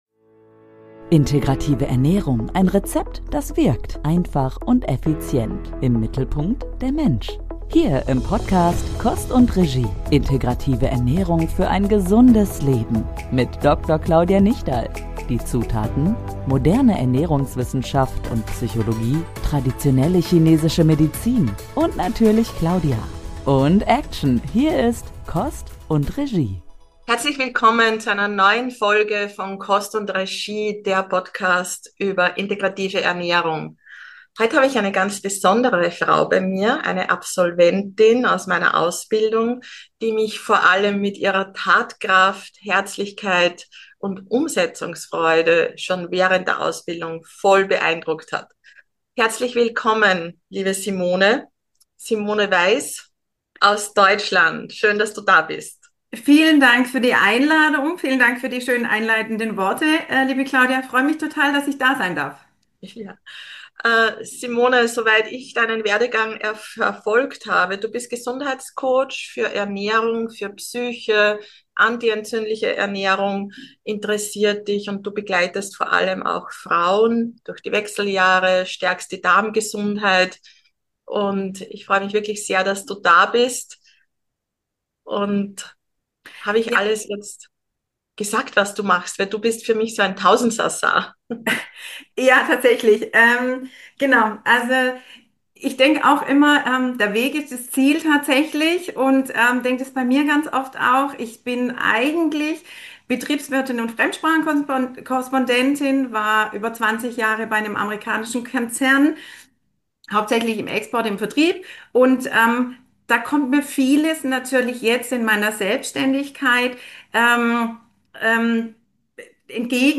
Ein ehrliches und motivierendes Gespräch über Mut, Veränderung und die Kraft, die entsteht, wenn Wissen in die Umsetzung kommt.